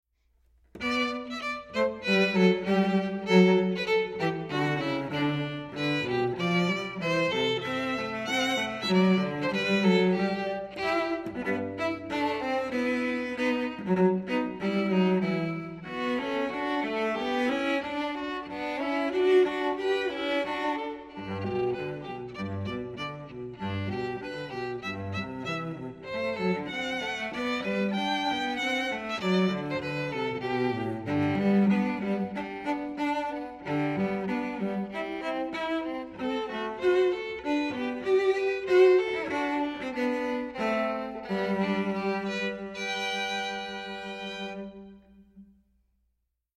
Voicing: Violin Duet